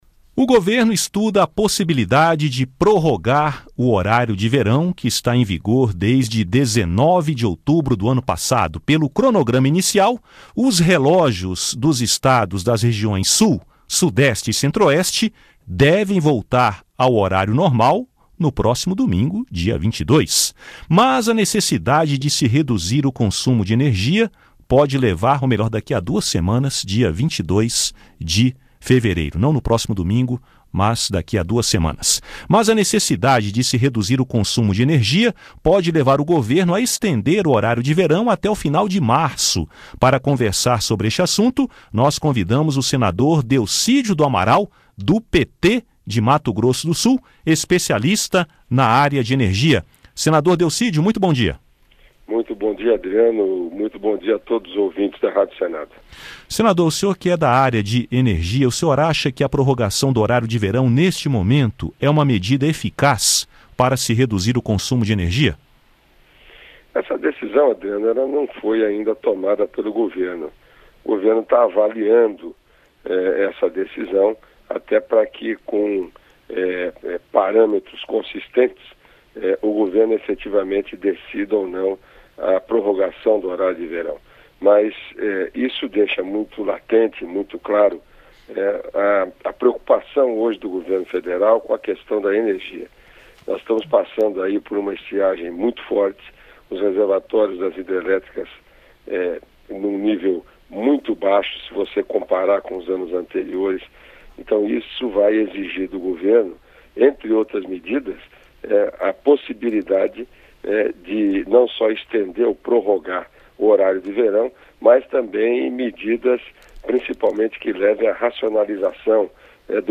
Entrevista com o senador Delcídio do Amaral (PT-MS).